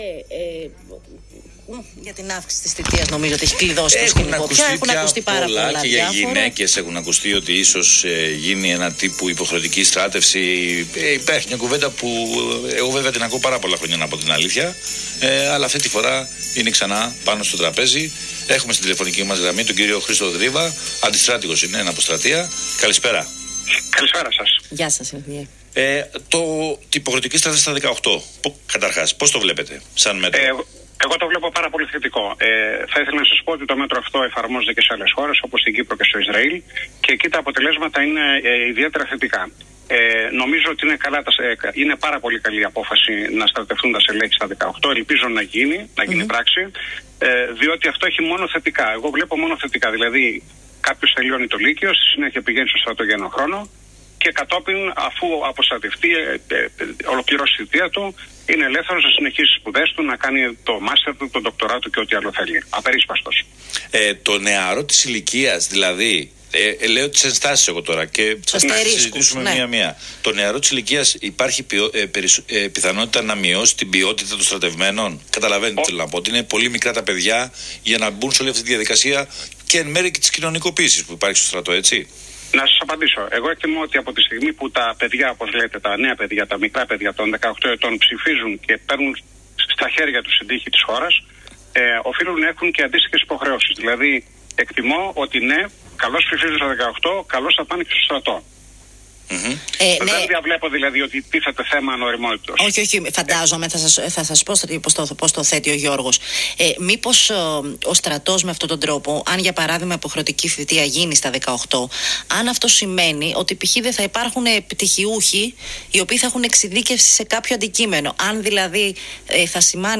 Σε συνέντευξη